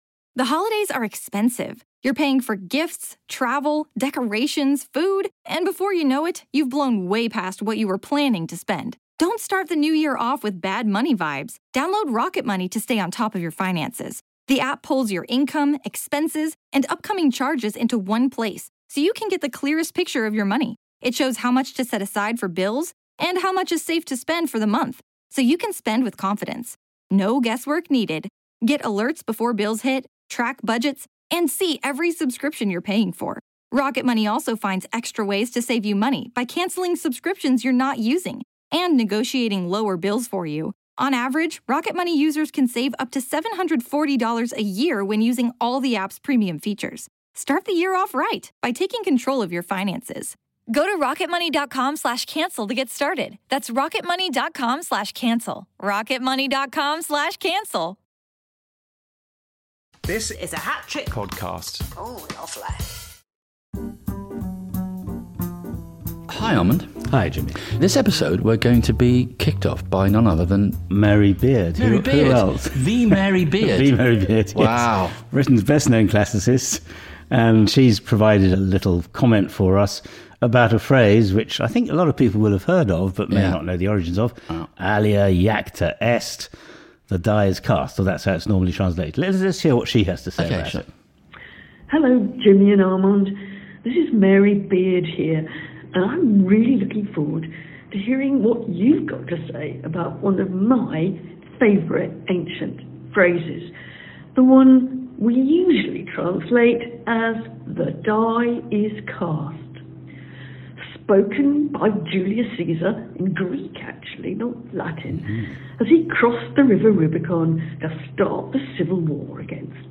With the help of Britain’s best-known classicist, Mary Beard, they unravel the famous phrase "The die is cast" and reveal why it doesn’t mean what most people think. Along the way, they explore Caesar’s love of Greek drama, the surprising truth about ancient dice (made from donkey knuckles!), and the gossipy brilliance of Suetonius, Rome’s ultimate scandal historian.